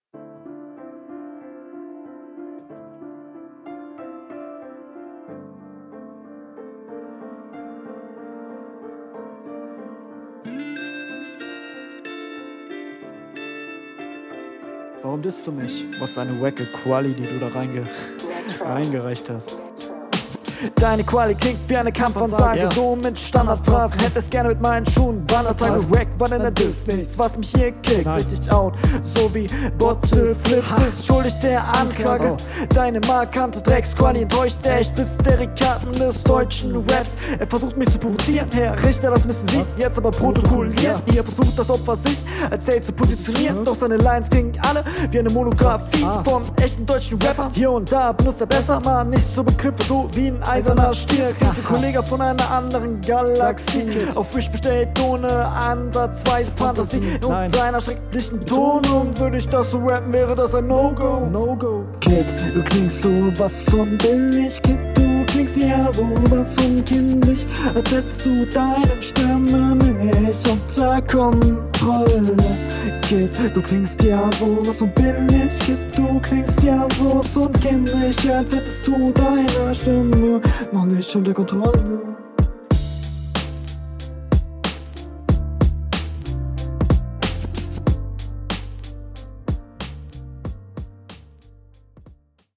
Cooler Piano Beat. Intro rauscht leicht aber ist okay.
Manchmal stammelst du übern beat, manchmal bist du gut on point.